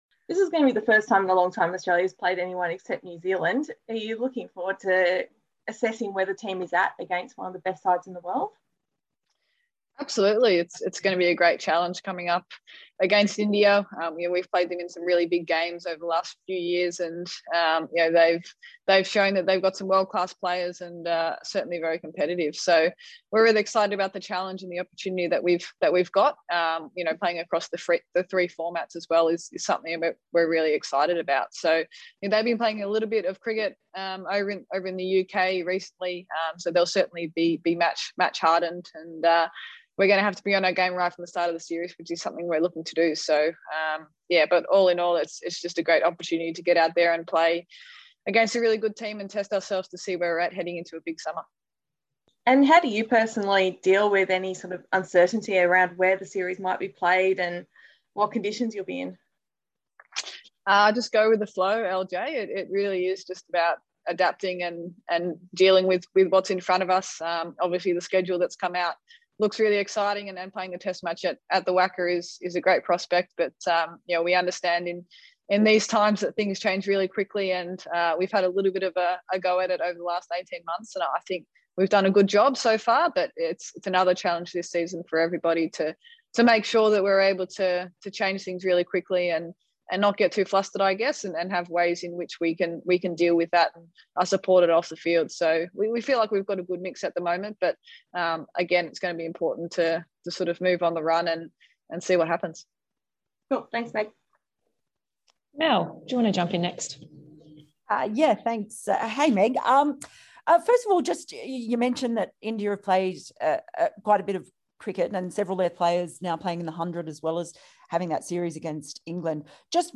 Meg Lanning speaks ahead of Australia v India